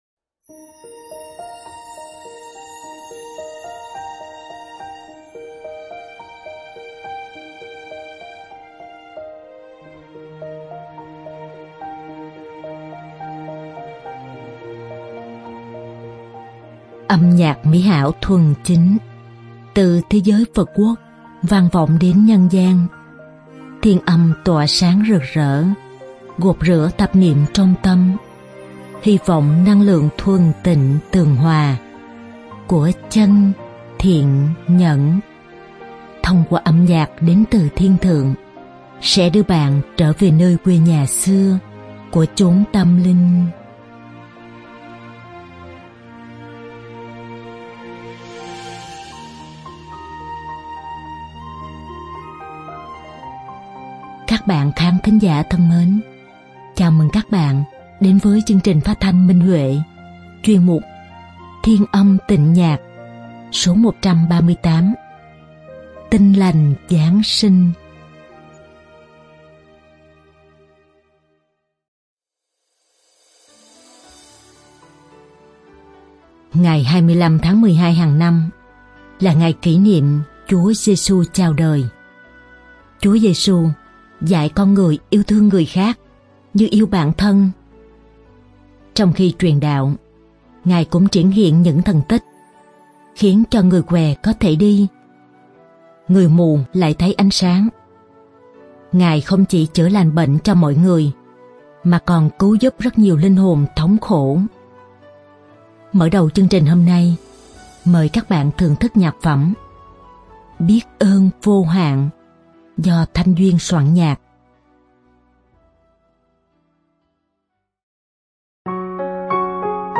Đơn ca nam
Đơn ca nữ